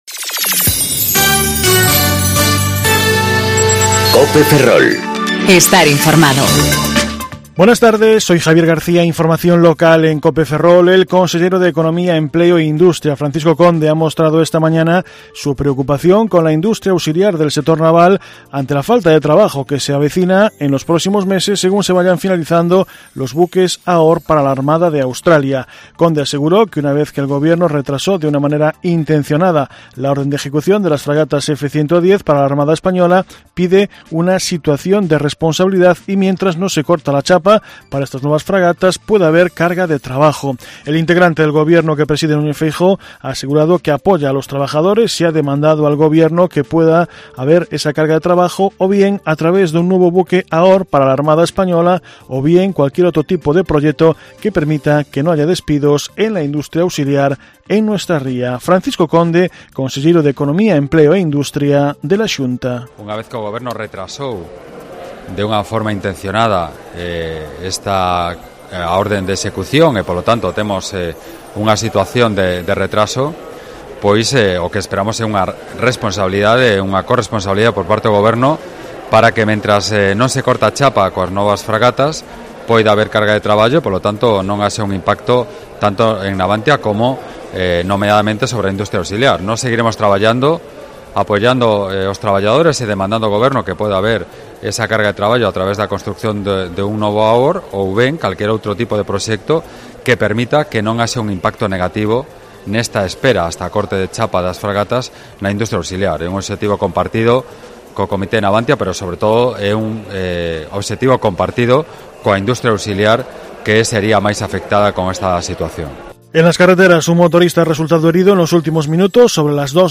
Informativo Mediodía Cope Ferrol 28/06/2019 (De 14.20 a 14.30 horas)